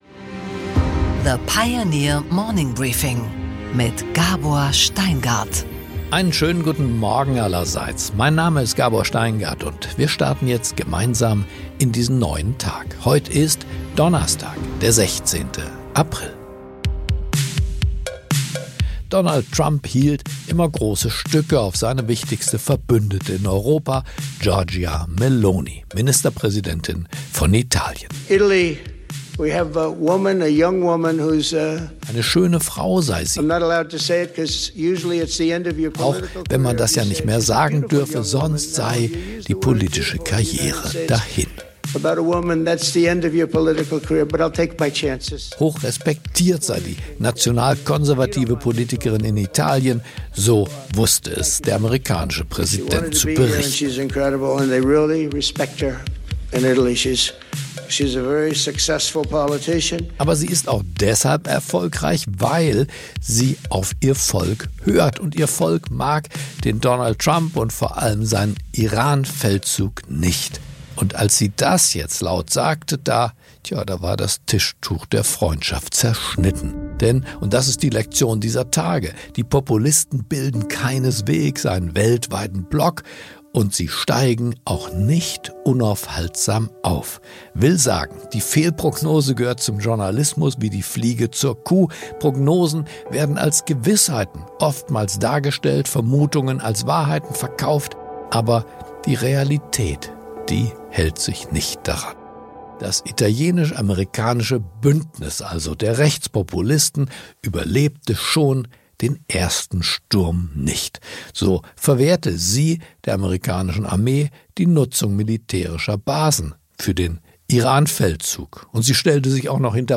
Gabor Steingart präsentiert das Morning Briefing.